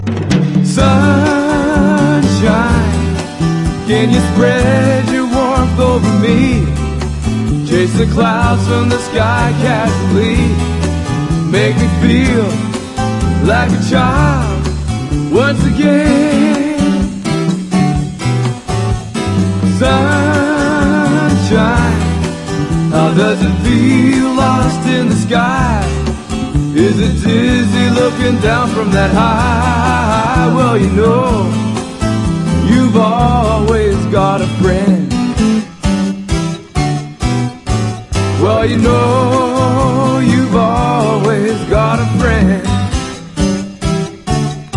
SOUL / SOUL / 70'S～ / RARE GROOVE
名作ブラック・サントラ！完璧なレア・グルーヴ・サウンド！
スリリングでグルーヴィー、そして時にスウィート！